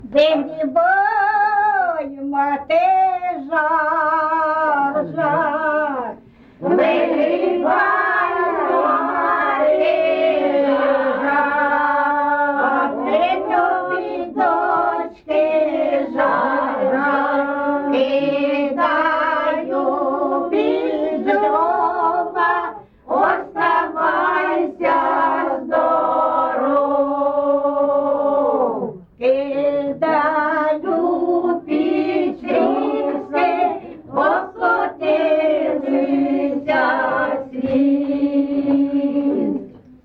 ЖанрВесільні
Місце записус. Шарівка, Валківський район, Харківська обл., Україна, Слобожанщина